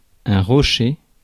Ääntäminen
Synonyymit pierre petite roussette Ääntäminen France: IPA: /ʁo.ʃe/ Tuntematon aksentti: IPA: /ʁɔ.ʃe/ Haettu sana löytyi näillä lähdekielillä: ranska Käännöksiä ei löytynyt valitulle kohdekielelle.